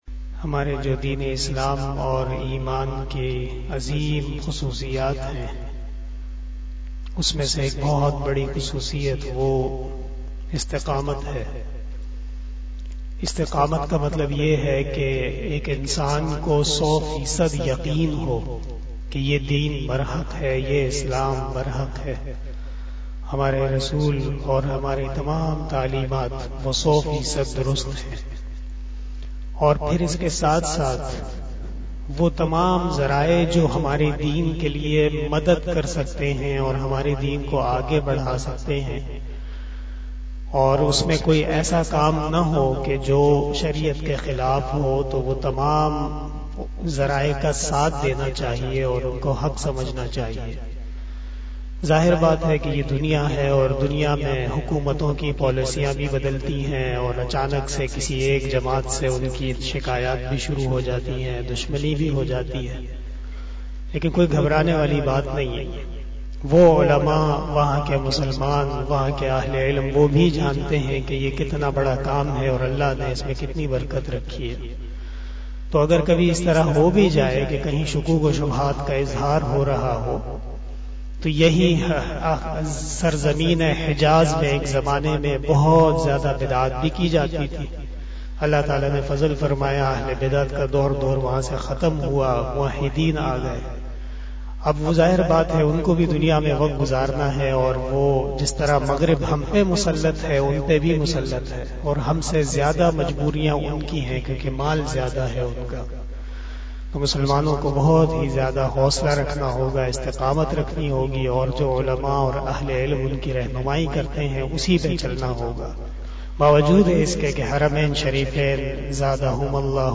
083 After Asar Namaz Bayan 12 December 2021 (06 Jamadal oula 1443HJ) Saturday